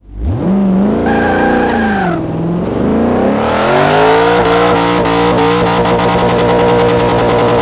Index of /server/sound/vehicles/tdmcars/hsvgts
rev.wav